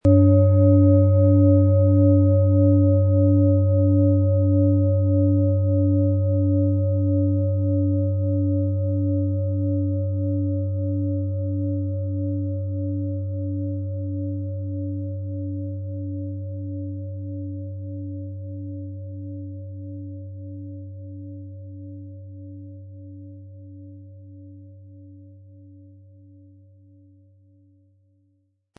Handgearbeitete Tageston Klangschale.
• Mittlerer Ton: DNA
Durch die traditionsreiche Fertigung hat die Schale vielmehr diesen kraftvollen Ton und das tiefe, innere Berühren der traditionellen Handarbeit.
PlanetentöneTageston & DNA
MaterialBronze